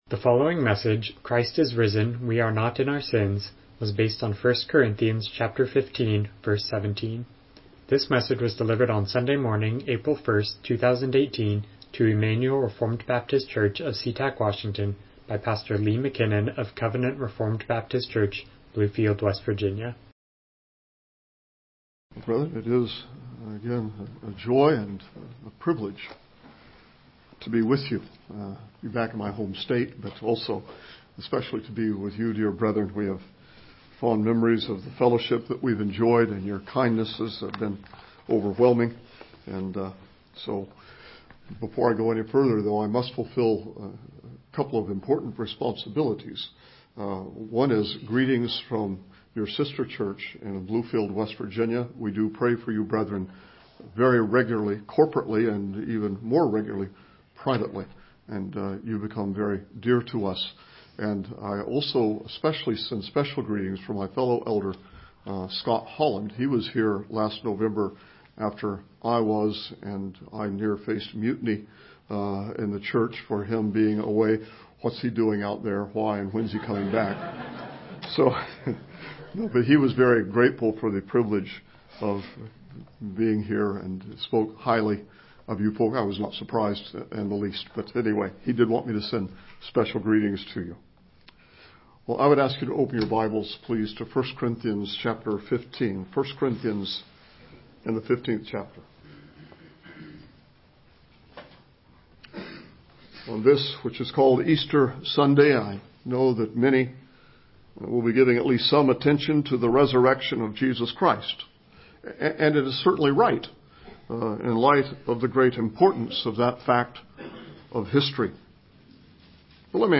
Miscellaneous Passage: 1 Corinthians 15:17 Service Type: Morning Worship « Who Can Be Saved?